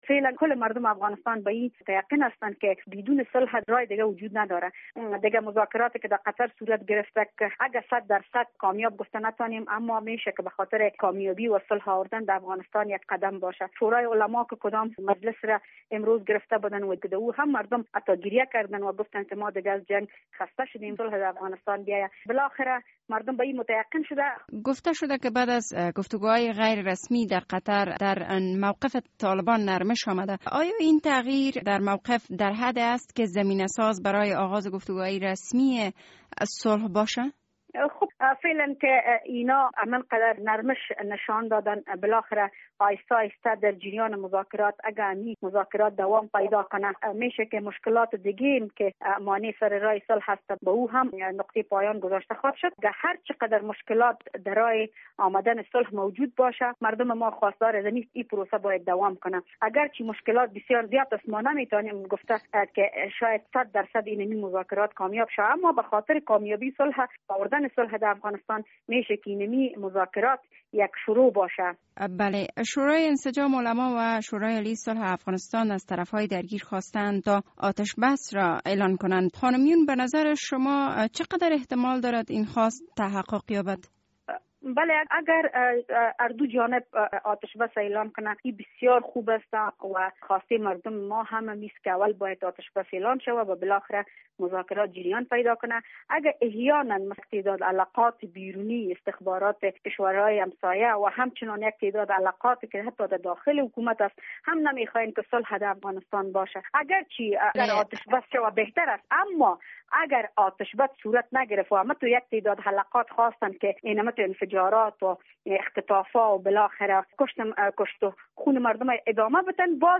مصاحبه در مورد چگونگی مساعد بودن شرایط برای آغاز گفتگو های صلح